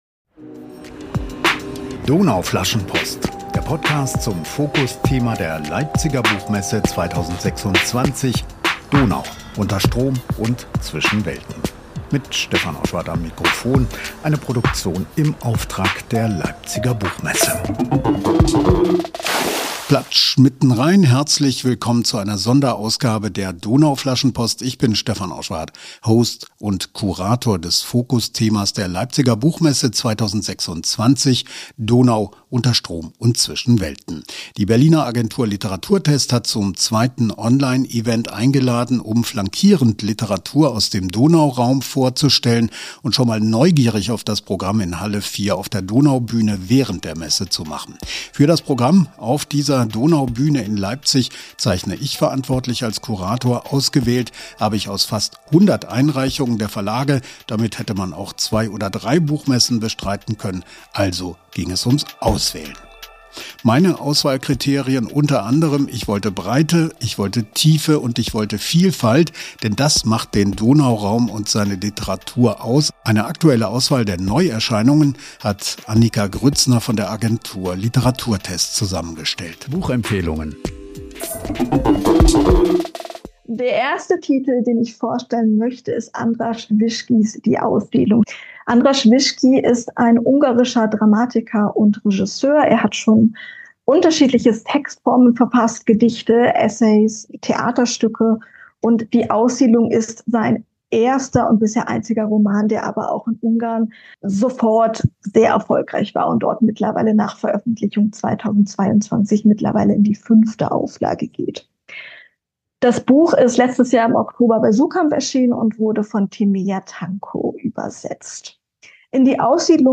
Zweites Online-Event der Berliner Agentur Literaturtest.